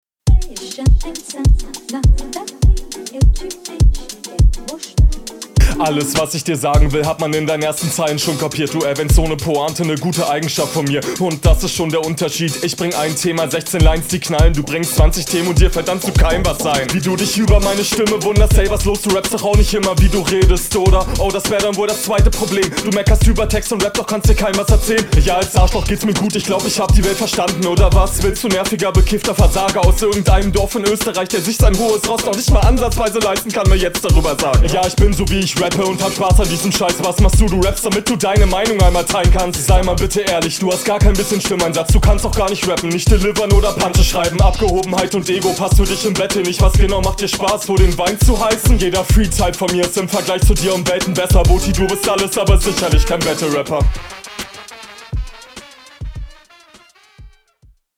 Oh hier ist die Mische aber noch schlechter als die HR, glaube da sind jetzt …
ok flow nochmal n stücken geiler als schon in der HR, hat sehr viel spaß …